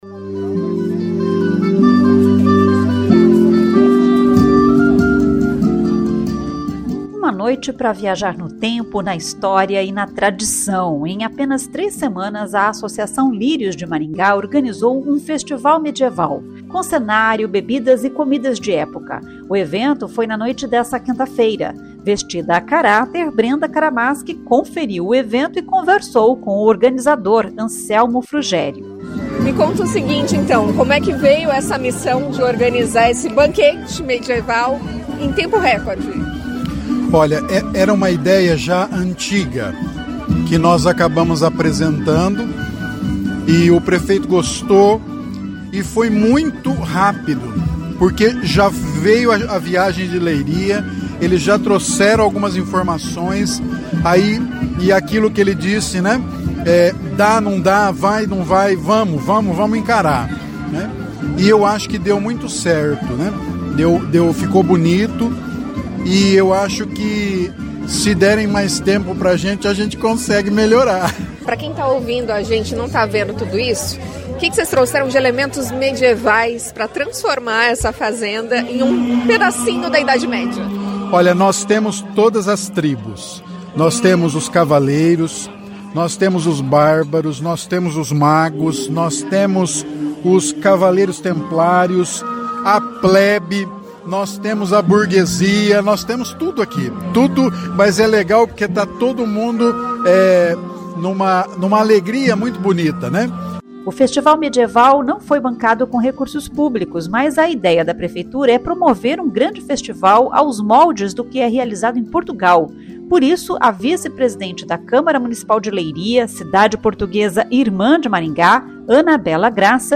Uma amostra do que pode ser o Festival Medieval foi apresentada a convidados, que se encantaram com o evento.
O prefeito Silvio Barros ficou animado com o resultado do evento, que dá uma amostra do que Maringá pode produzir.